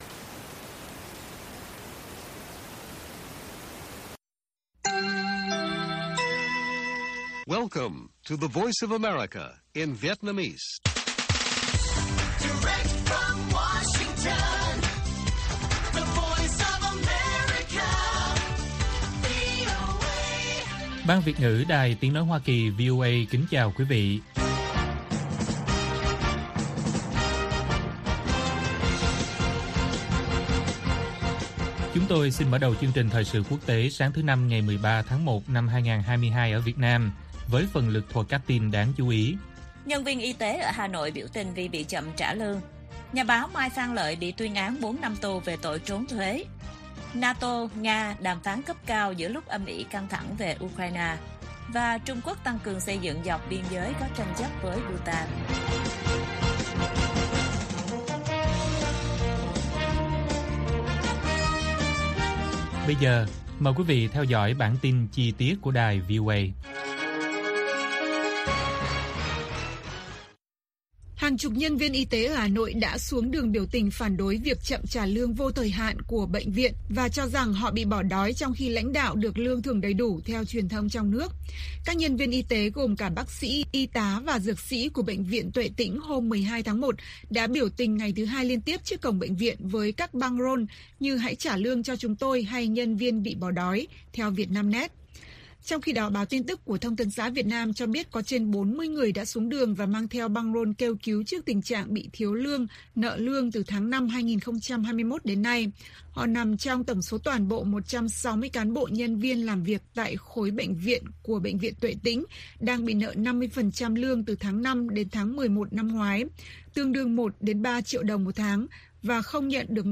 Bản tin VOA ngày 13/1/2022